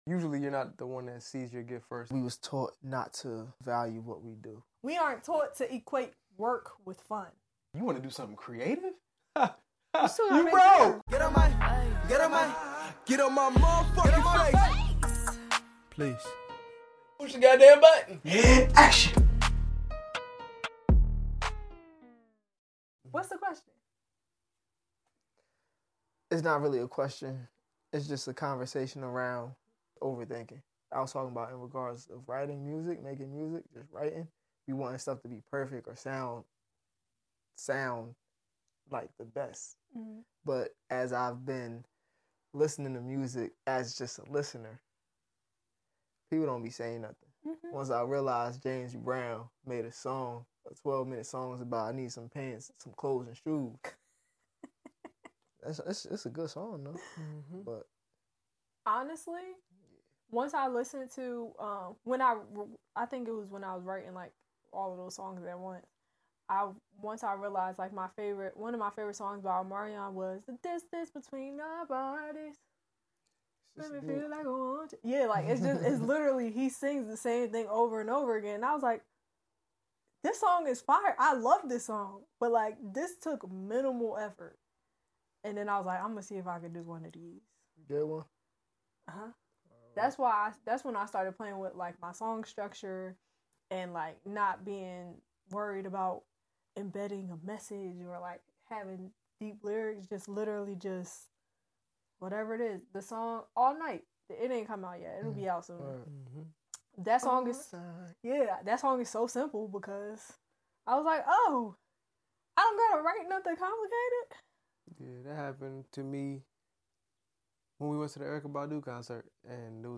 We love to laugh, create, and inspire while having unique and thought provoking conversations.